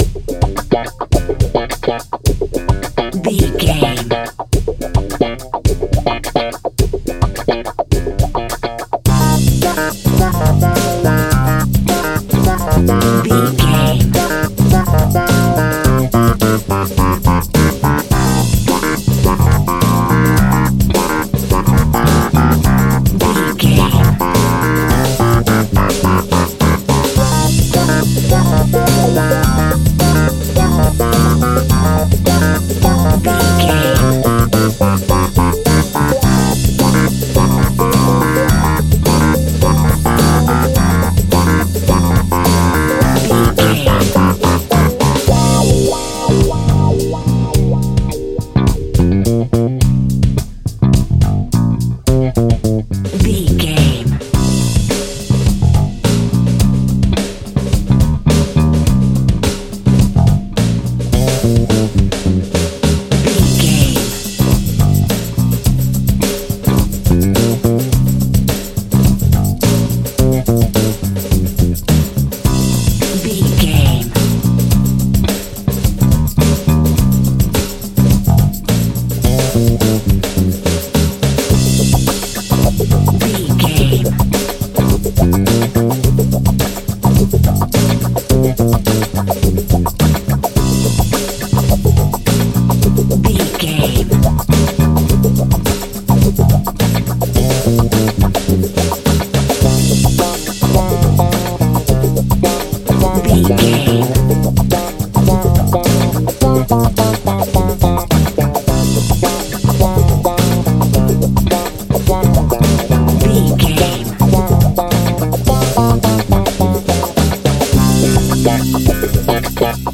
Aeolian/Minor
funky
lively
electric guitar
electric organ
drums
bass guitar
saxophone
percussion